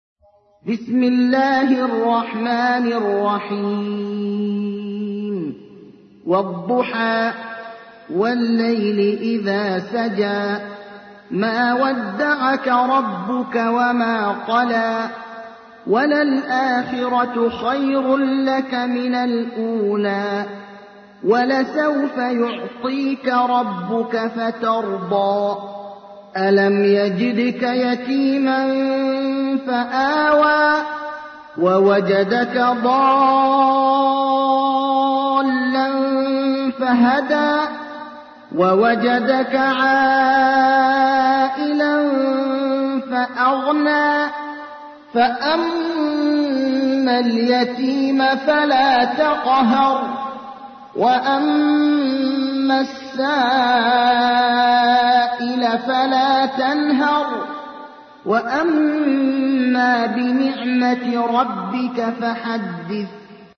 تحميل : 93. سورة الضحى / القارئ ابراهيم الأخضر / القرآن الكريم / موقع يا حسين